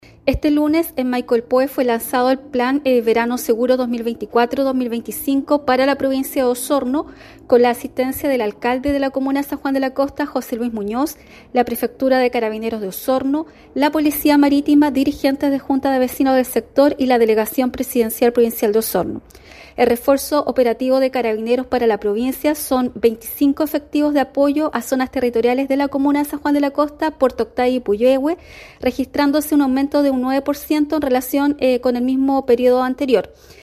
Por su parte la delegada presidencial provincial, Claudia Pailalef Montiel, señaló: “así es, carabineros se da a una tarea de hace años  para poder garantizar un verano seguro para todas las personas que vienen a disfrutar de estos hermosos parajes»